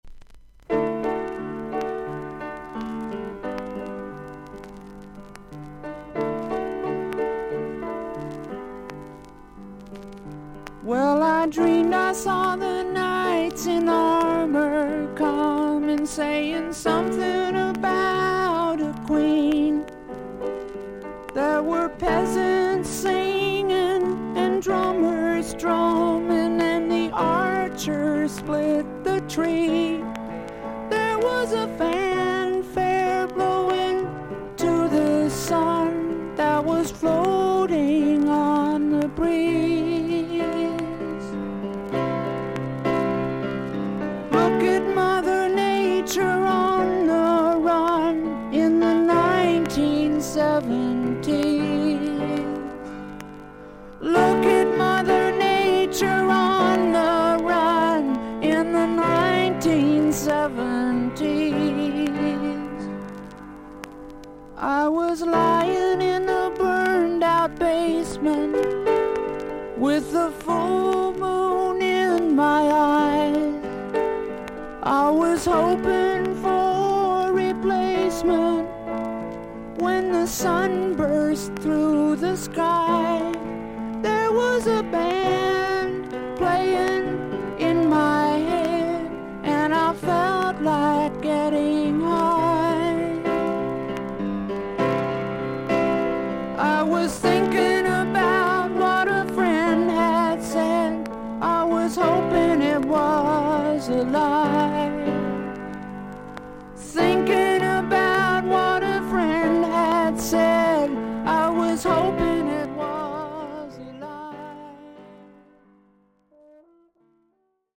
アメリカ盤 / 12インチ LP レコード / ステレオ盤
A2の最初からA3序盤まで1.3cmのキズ、周回ノイズがあります。
ほかはVG+：所々に少々軽いパチノイズの箇所あり。少々サーフィス・ノイズあり。音自体はクリアです。